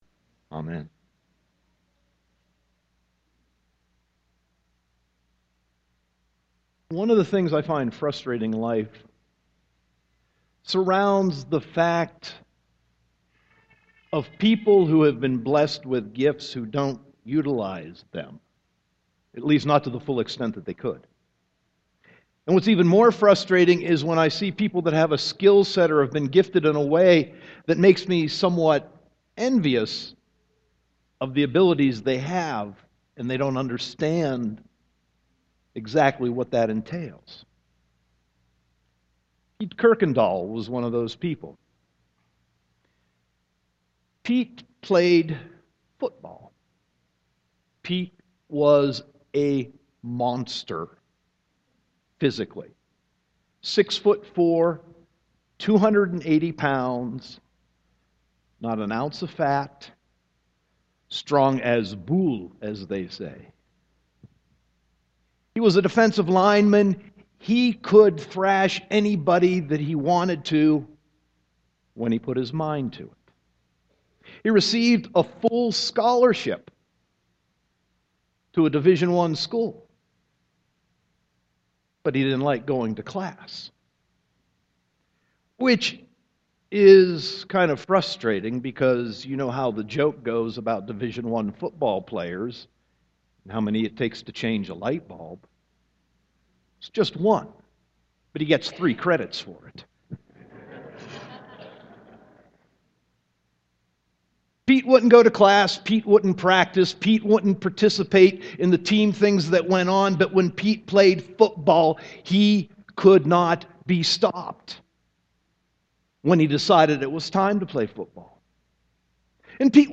Sermon 8.24.2014